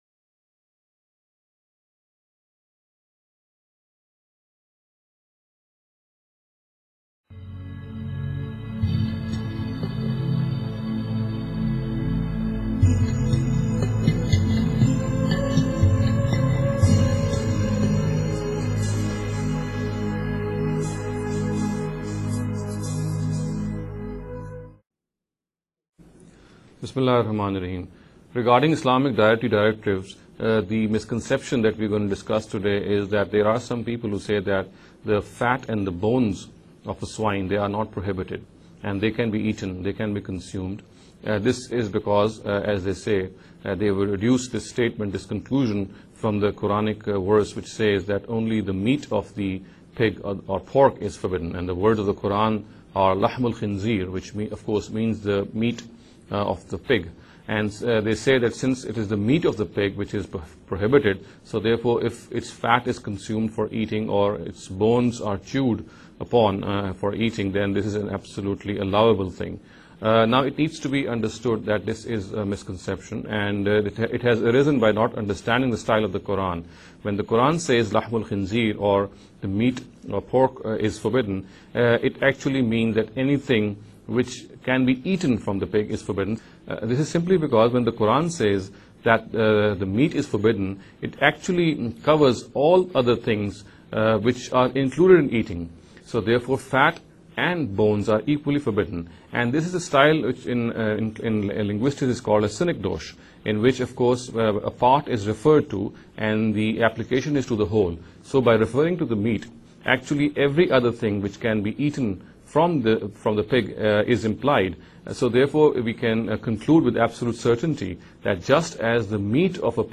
This lecture series will deal with some misconception regarding The Dietary Directives of Islam. In every lecture he will be dealing with a question in a short and very concise manner. This sitting is an attempt to deal with the question 'Are the Bones and Fat of a Swine not Prohibited?’.